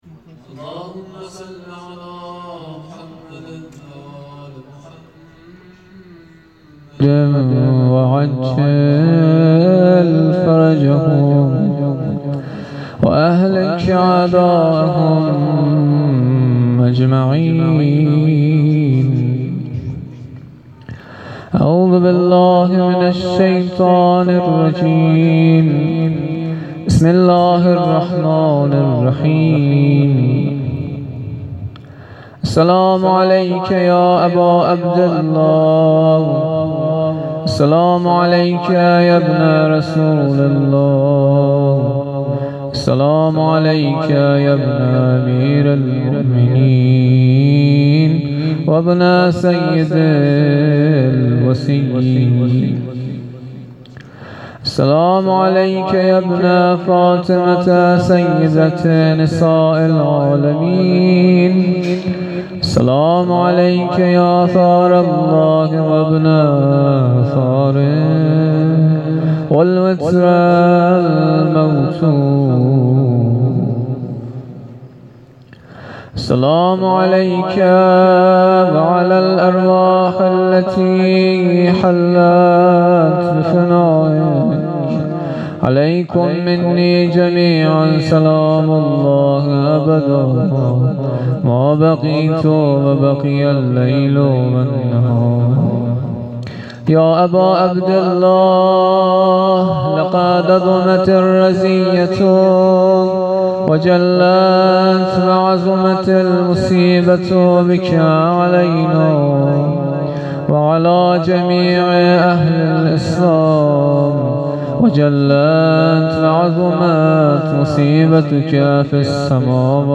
شب پنجم محرم 98 - زیارت عاشورا